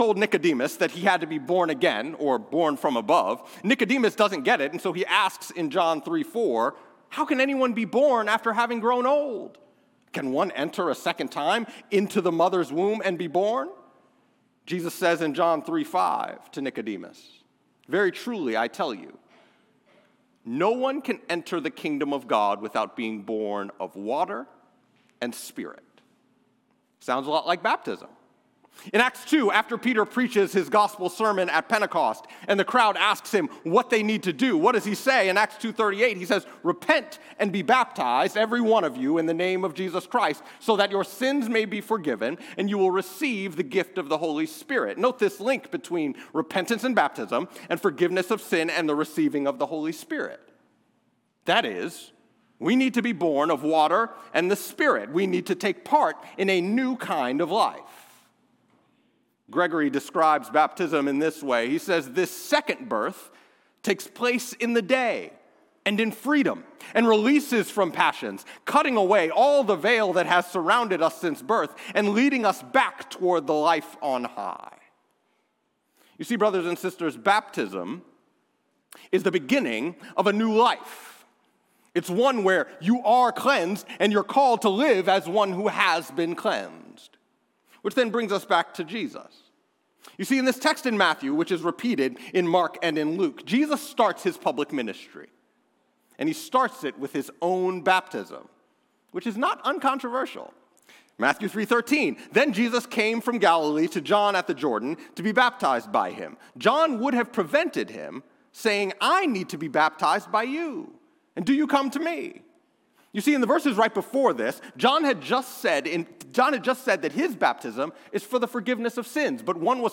10AM Service Jan 11th 2026